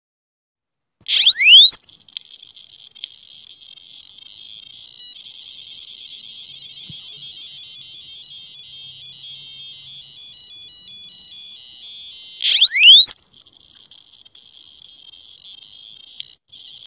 Po spuštění programu se ozve skřípot a pak různé podivné zvuky. Po několika sekundách se smyčka znovu spustí a ozve se další krátké zaskřípění.
buzzer_bez_generatora_piezo_6.mp3